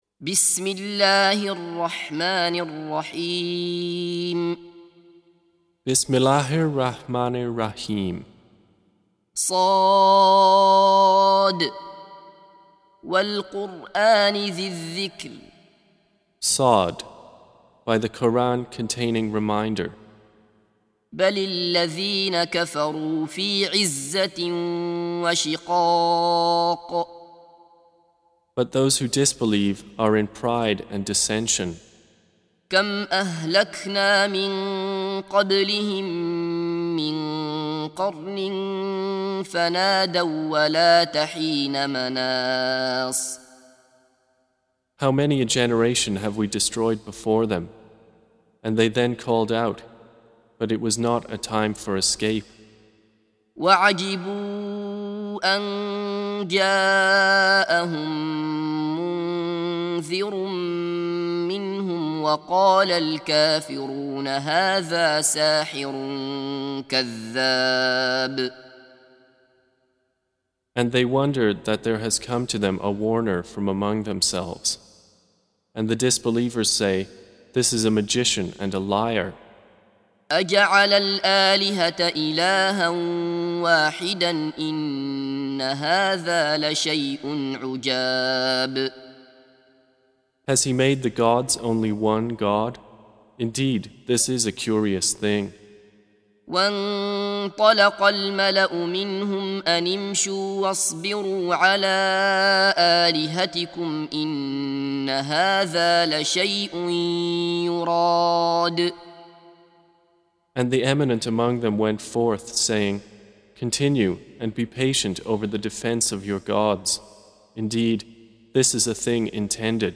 Recitation
Surah Sequence تتابع السورة Download Surah حمّل السورة Reciting Mutarjamah Translation Audio for 38. Surah S�d. سورة ص N.B *Surah Includes Al-Basmalah Reciters Sequents تتابع التلاوات Reciters Repeats تكرار التلاوات